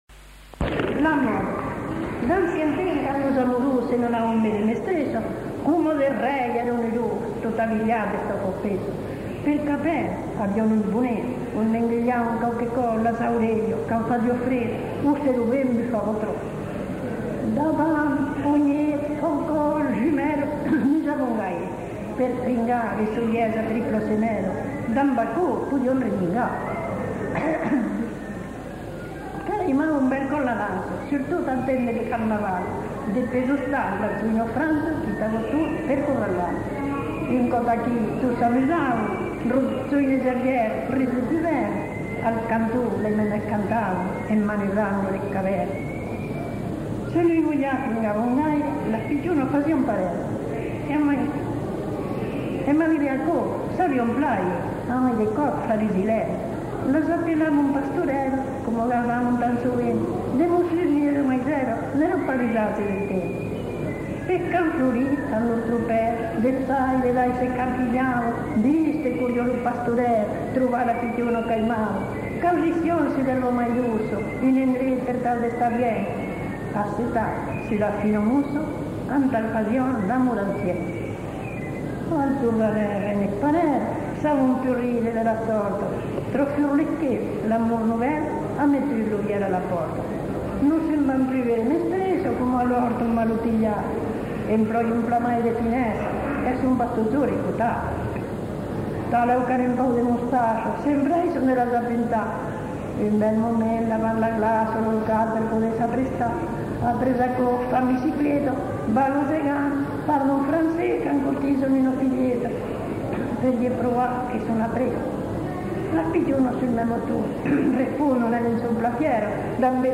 Aire culturelle : Haut-Agenais
Lieu : Cancon
Genre : conte-légende-récit
Effectif : 1
Type de voix : voix de femme
Production du son : récité
Classification : monologue